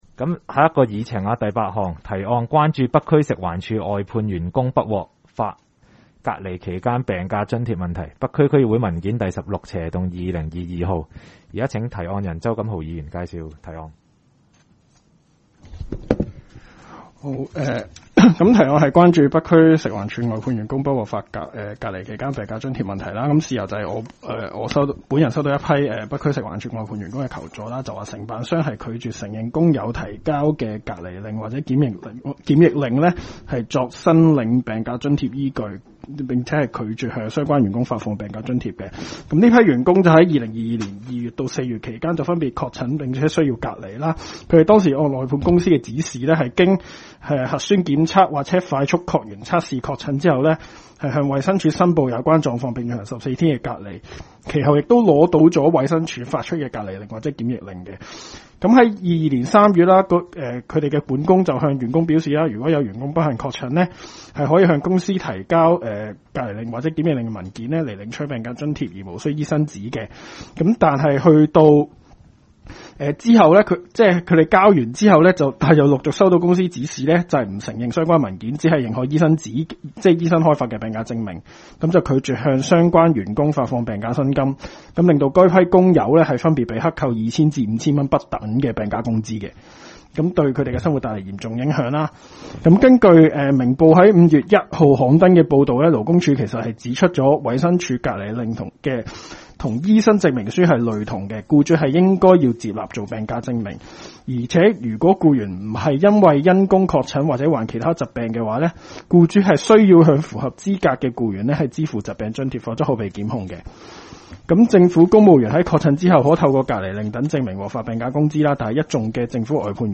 区议会大会的录音记录
北区区议会第十一次会议
北区民政事务处会议室